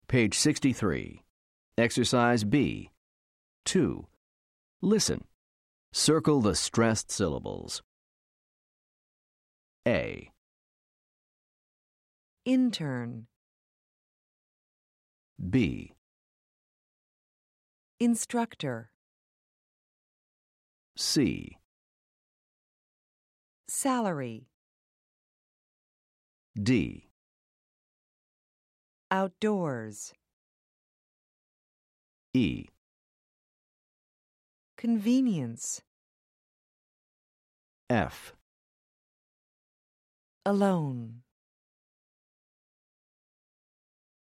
American English
Class Audio CDs include natural conversational recordings for the listening tasks in each unit, pronunciation practice, and expansion units containing authentic student interviews.